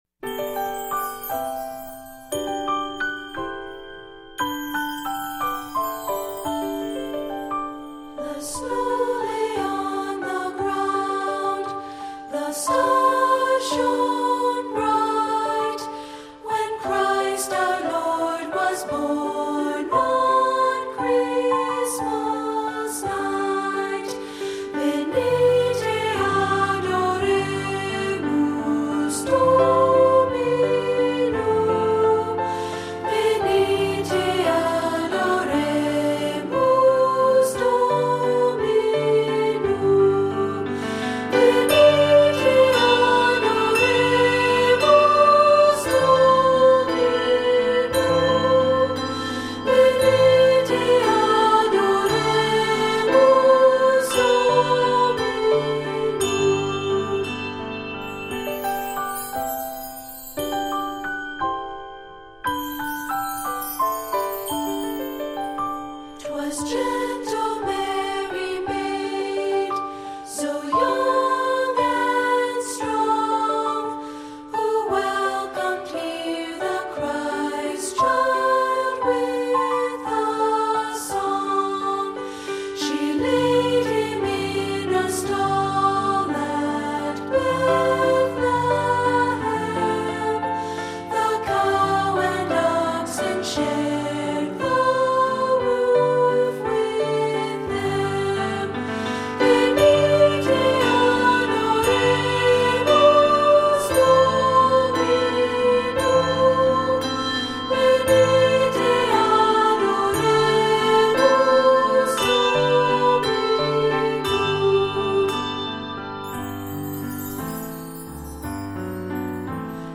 Composer: Anglo-Irish Carol
Voicing: Unison